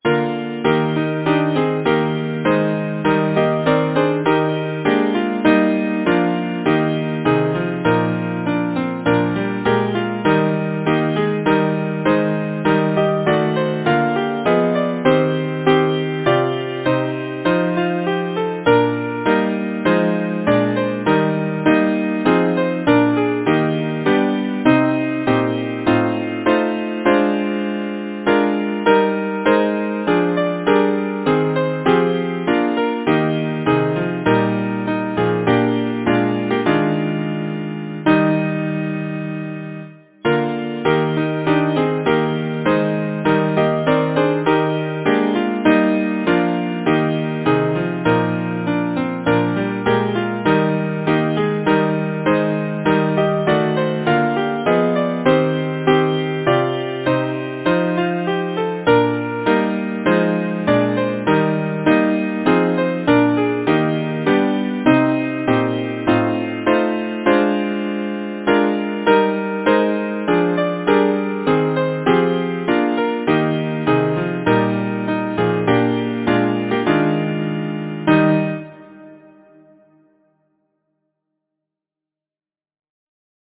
Title: O Summer Wind Composer: William Alexander Campbell Cruickshank Lyricist: F. S. Parrycreate page Number of voices: 4vv Voicing: SATB Genre: Secular, Partsong
Language: English Instruments: A cappella